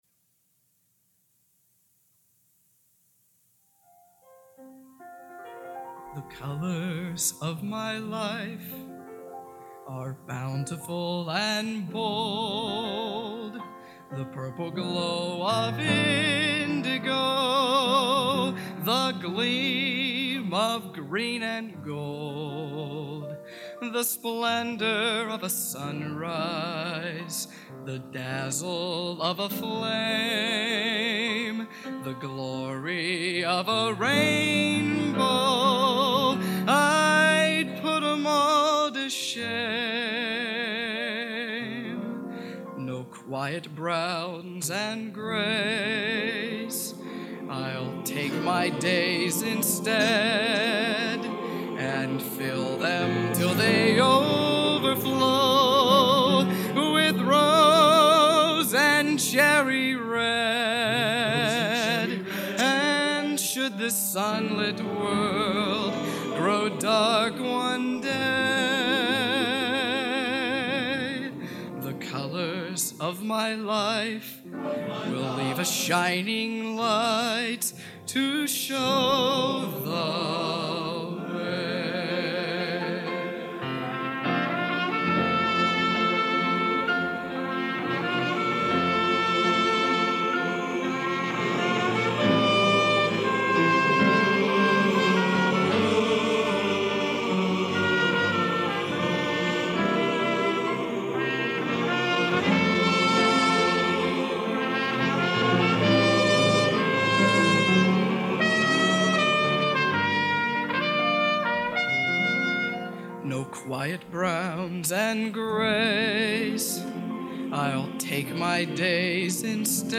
Genre: Broadway | Type: End of Season